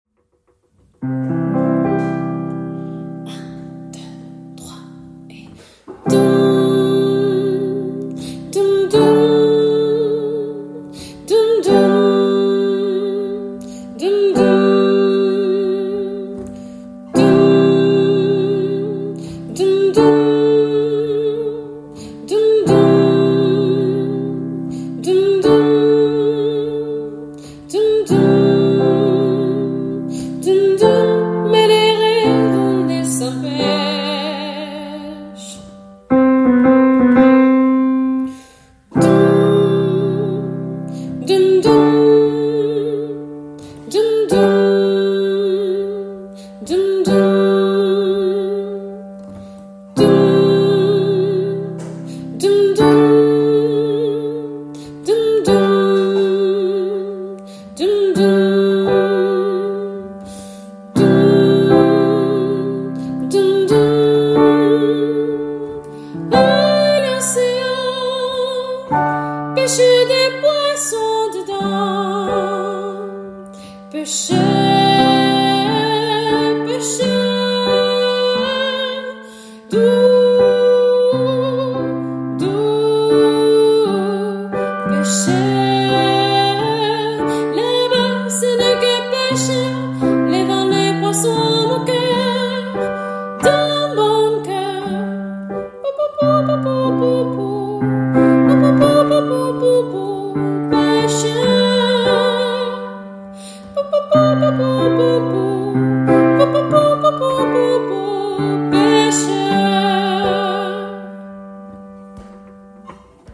Soprane